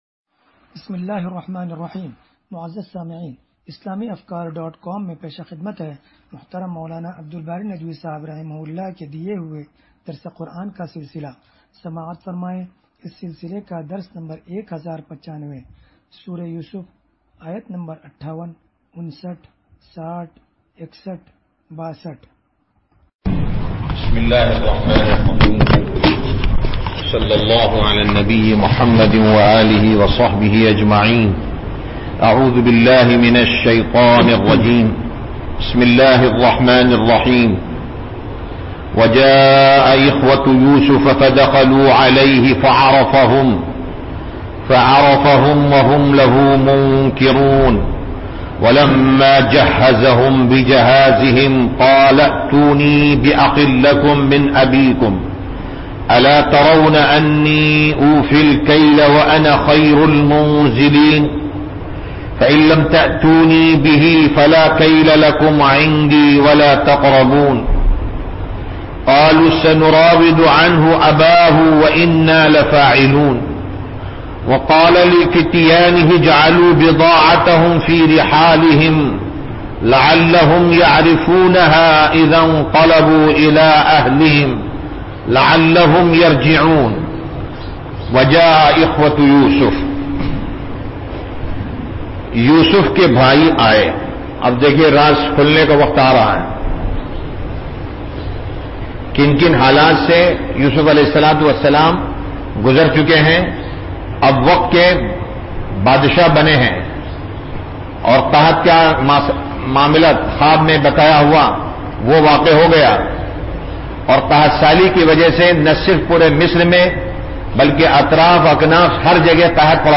درس قرآن نمبر 1095